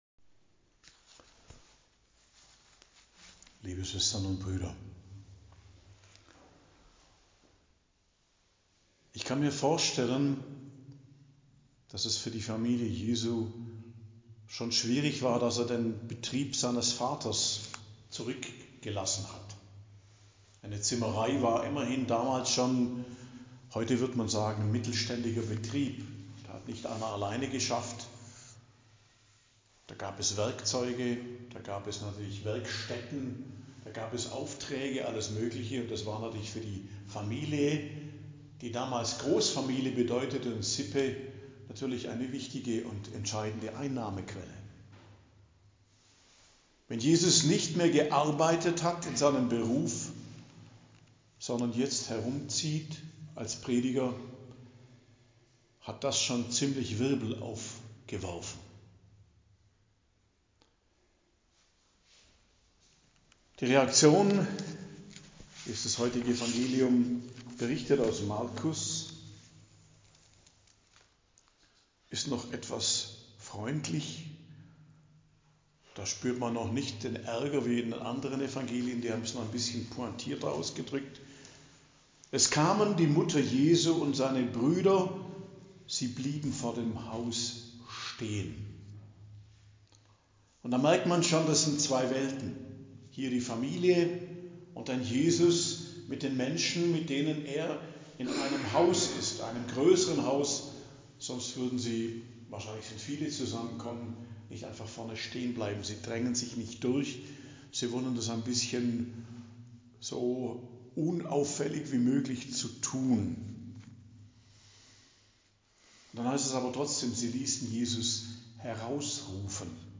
Predigt am Dienstag der 3. Woche i.J. 28.01.2025